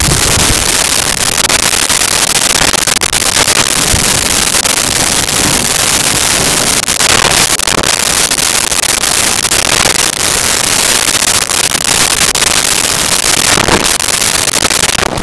Underwater Sloshing
Underwater Sloshing.wav